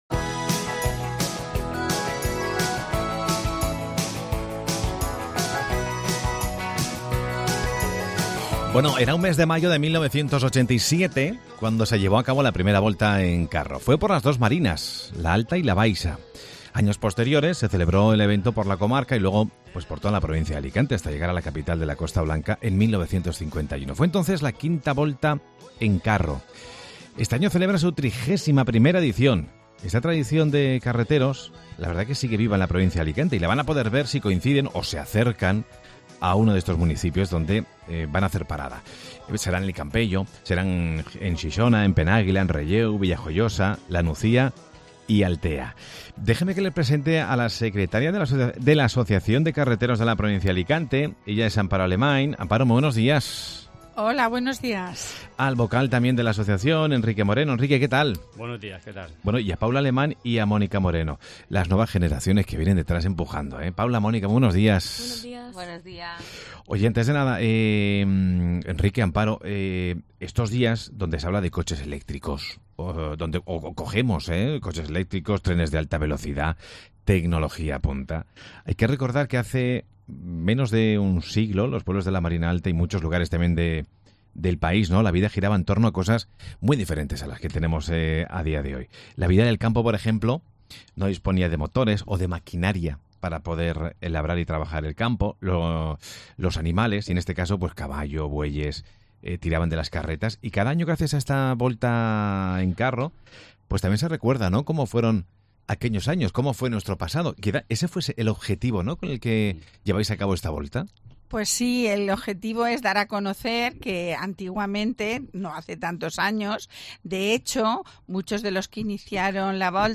Hoy han pasado por Mediodía COPE varios miembros de la Asociación de Carreteros de la provincia de Alicante para contar detalles de cómo será esta aventura y para recordar cómo era y es esta profesión.